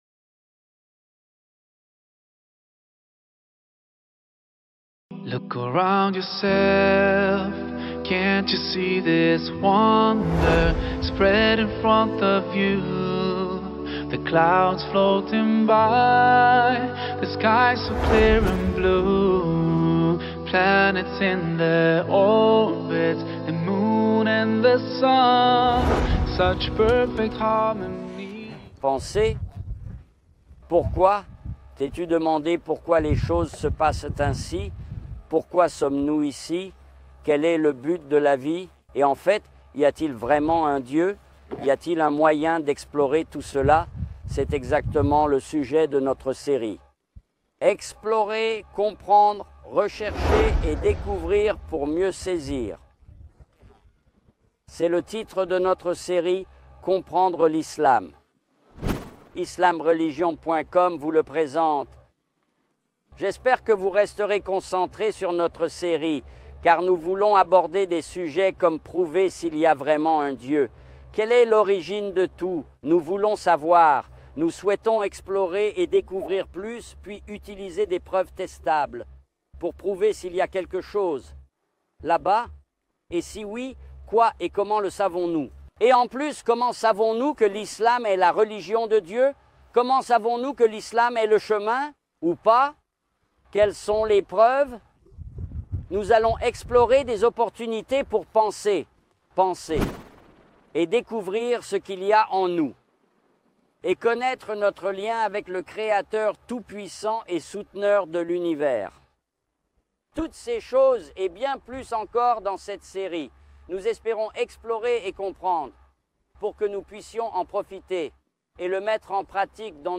filmée dans le décor de paysages pittoresques et de lieux historiques de Jordanie. Dans cet épisode, il présente la série.